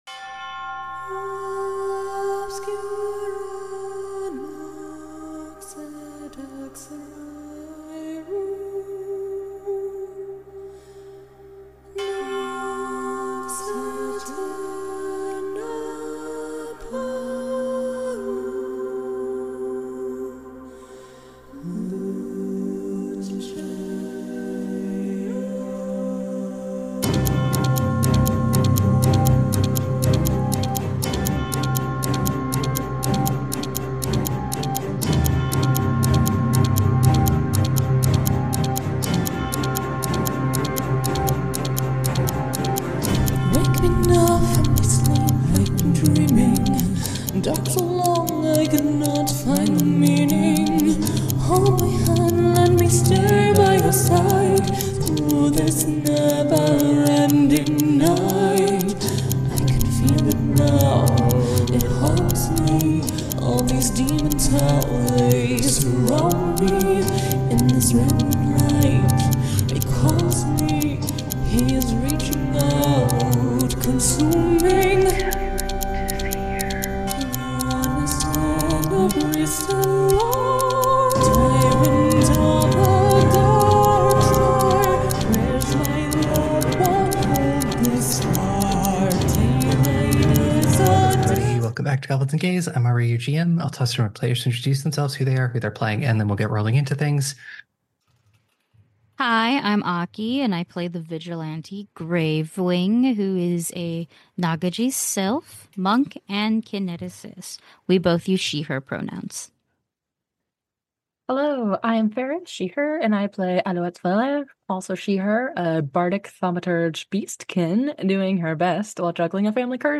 A gaggle of gays play Pathfinder 2e, our flagship campaign Blood of Kings releases every Wednesday 9AM EST!